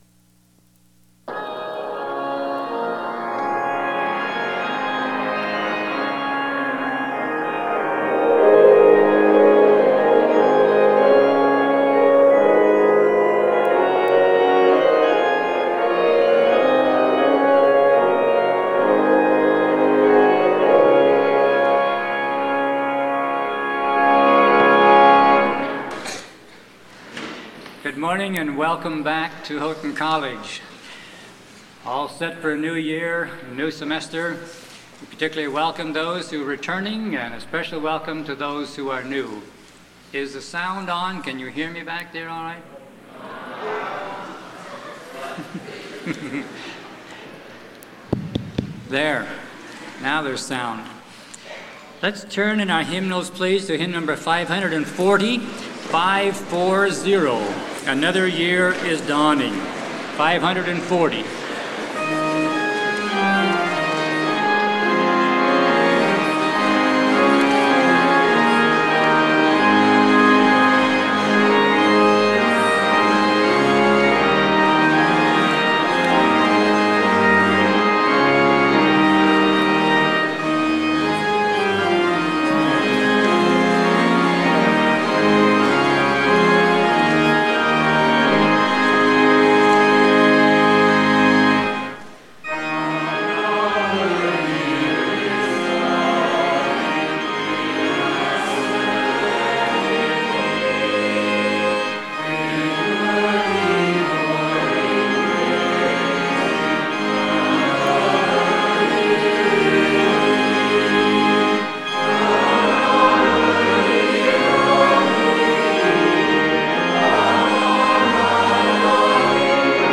A message for the new year